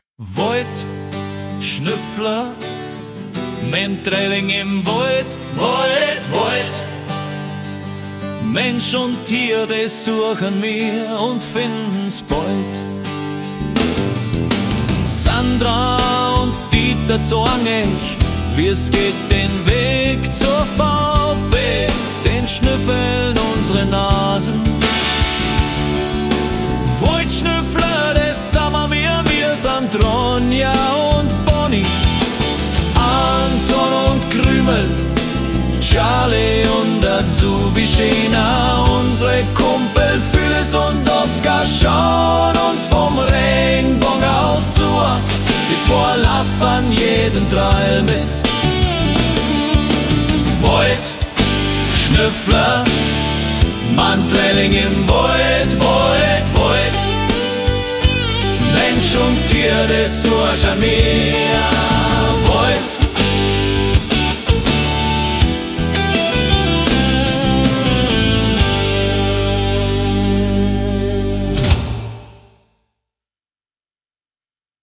Unser WoidSchnüffler® Song seit 02.10.2025 - Austropop